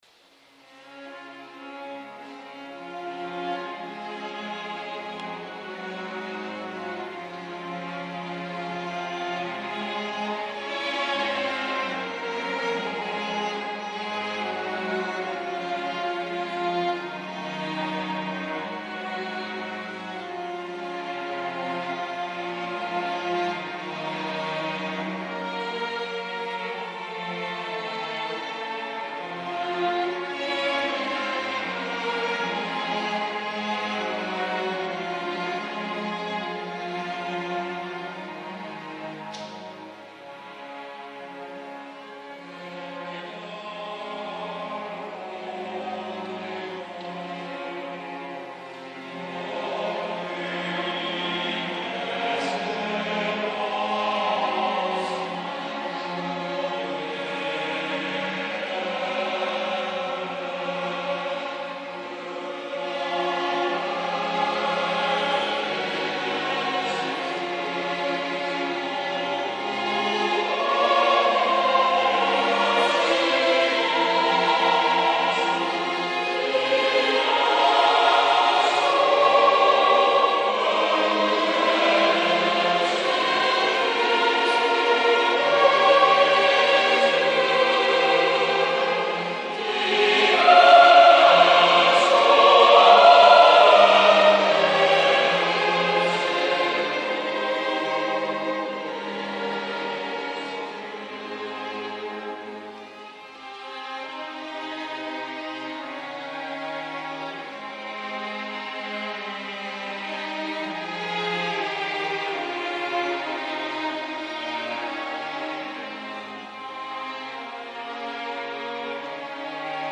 Ensemble Vocal Philharmonia de Marseille